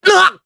Evan-Vox_Damage_jp_03.wav